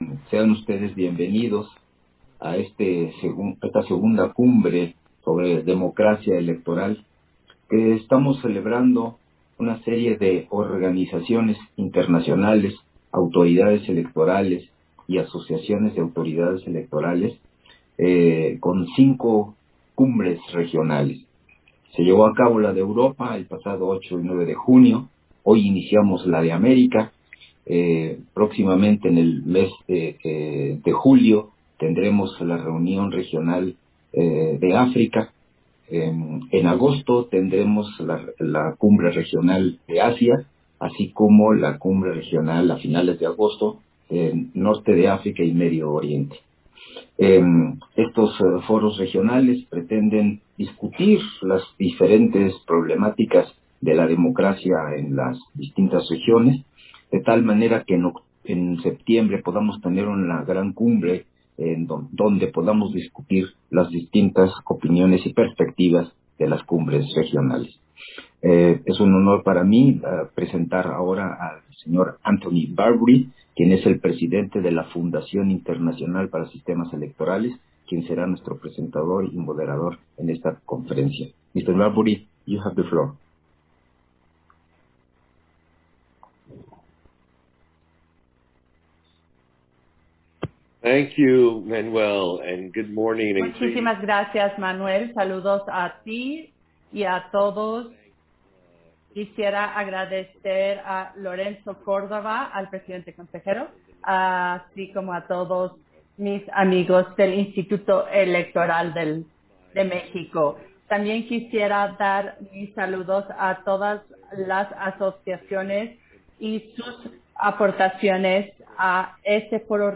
280622_AUDIO_BIENVENIDA-AL-FORO-REGIONAL-PARA-AMÉRICA-CONFERENCIA-MAGISTRAL-EXPRESIDENTE-DE-COSTA-RICA - Central Electoral